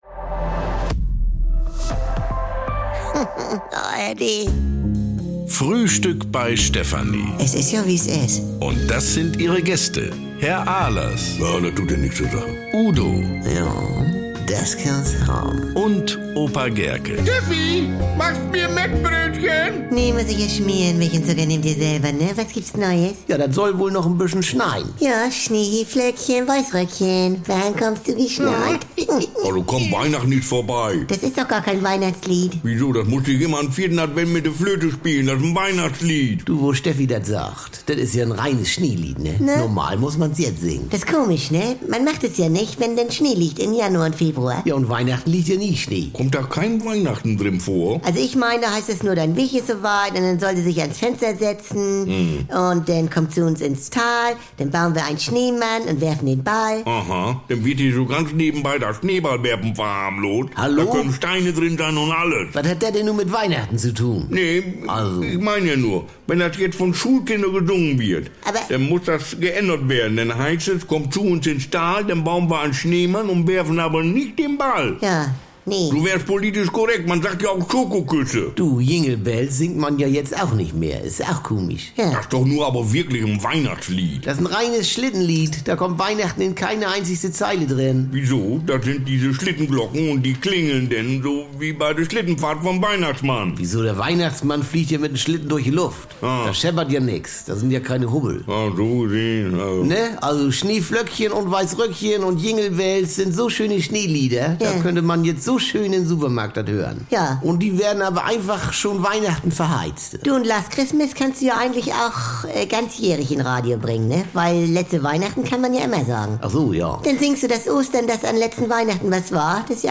Der Norden ist schön weiß, überall Schnee. Steffi singt deshalb